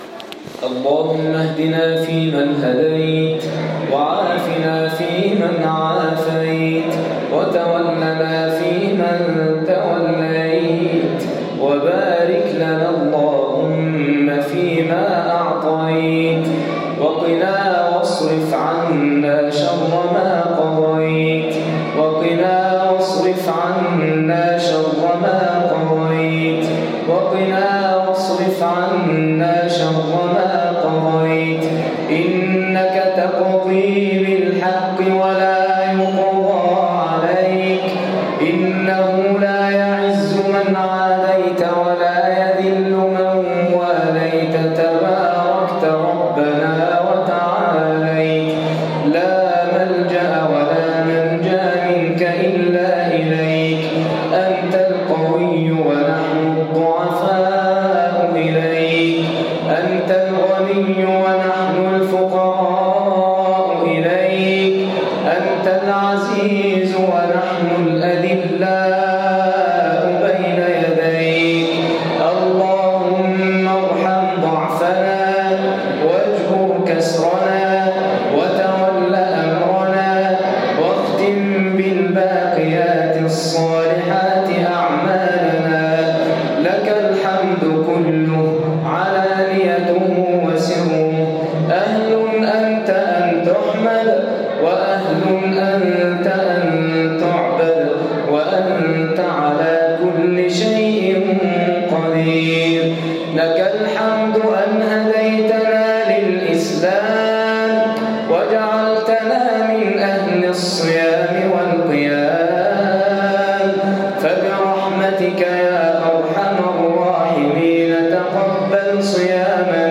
دعاء خاشع ليلة 27 رمضان 1443هـ
تسجيل لدعاء خاشع ومؤثر